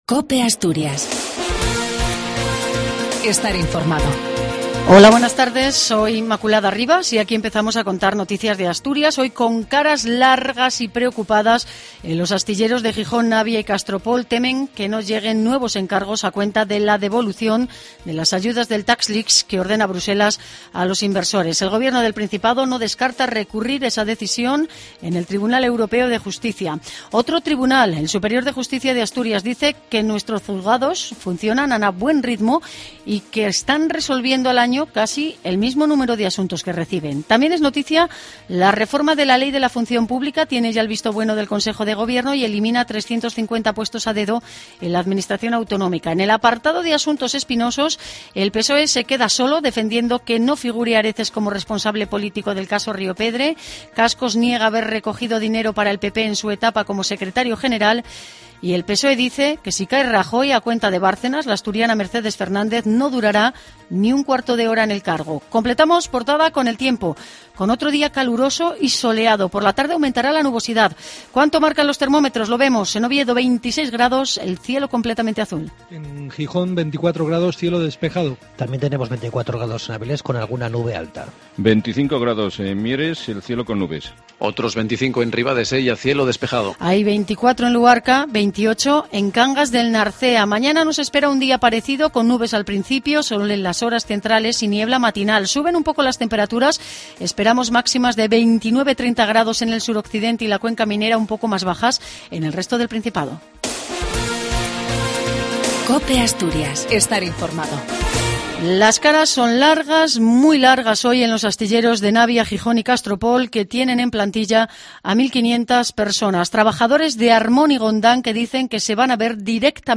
AUDIO: LAS NOTICIAS DE ASTURIAS AL MEDIODIA.